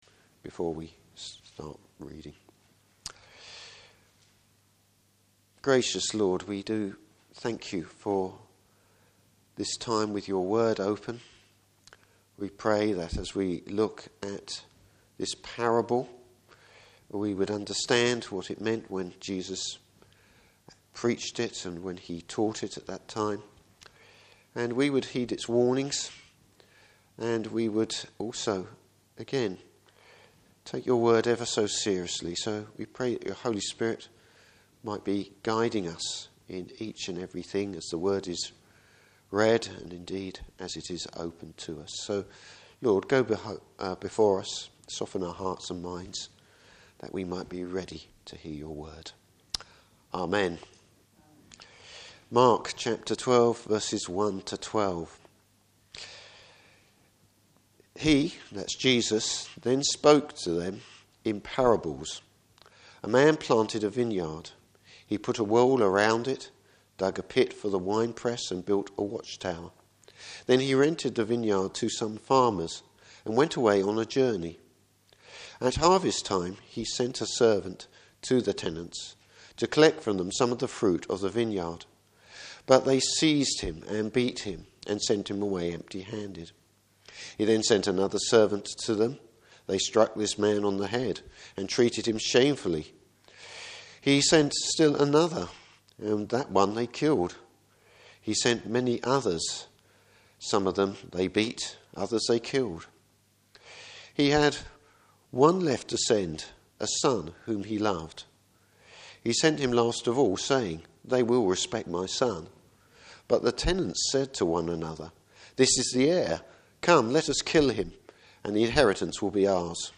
Service Type: Morning Service The religious leaders get a major shock!